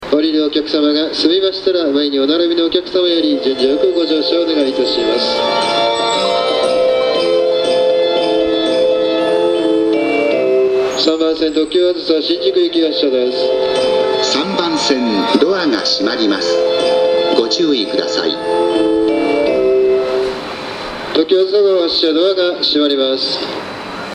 当駅は収録環境が悪く、なかなかメロディーを綺麗に収録できません。
2コーラス
1.1コーラス（2コーラス）です!特急だとフルコーラス以上鳴りやすくなります。